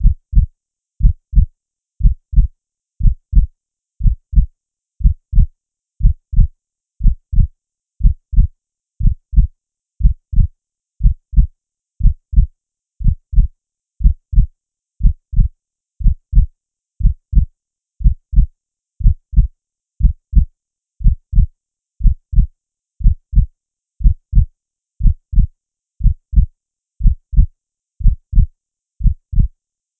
slow heartbeat and a
heartbeat_slow.wav